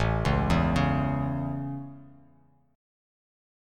A Chord
Listen to A strummed